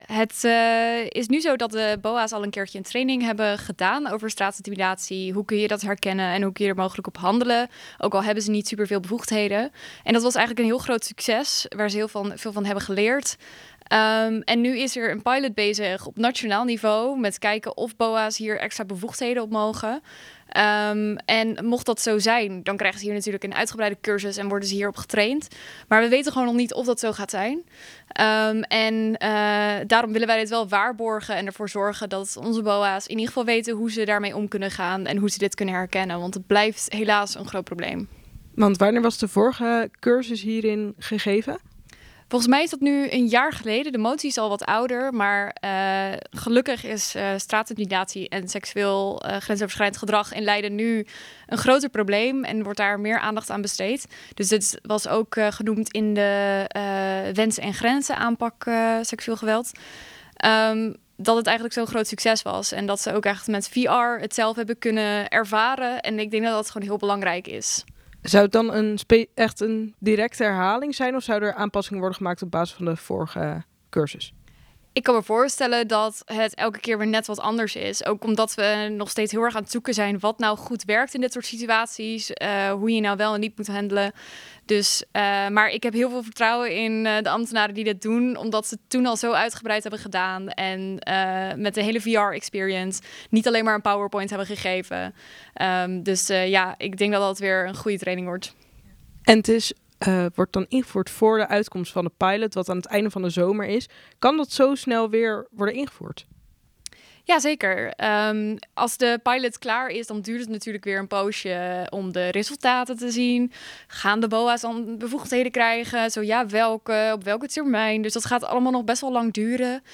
gaat in gesprek met fractievoorzitter van Studenten voor Leiden, Elianne Wijnands over het voorstel en de pilot